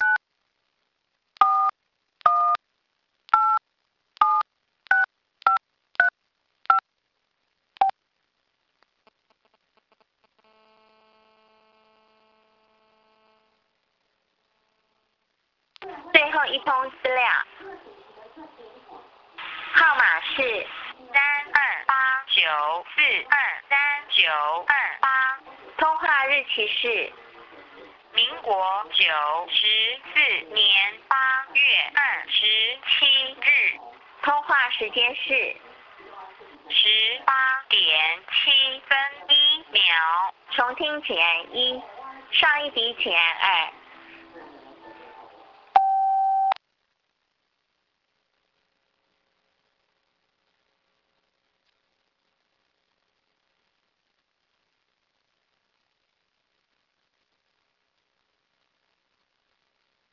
• 以電腦語音告知隱藏來電者之號碼。